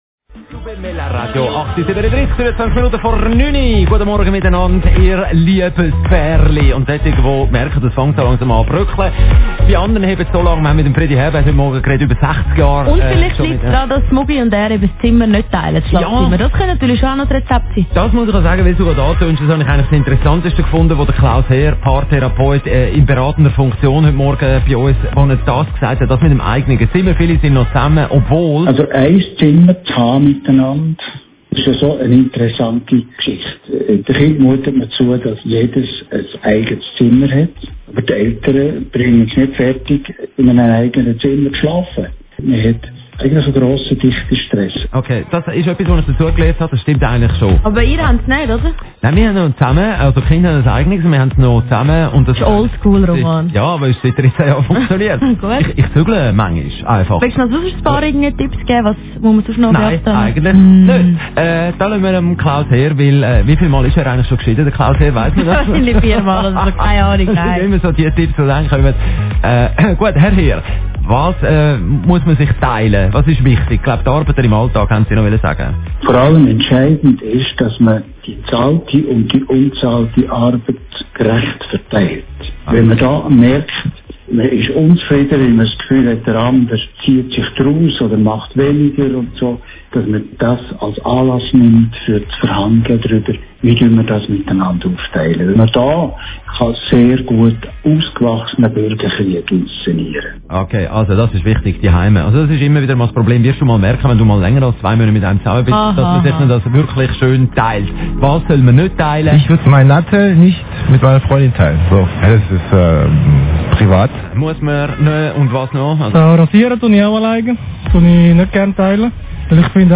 Radio Energy, am 5. Juni 2017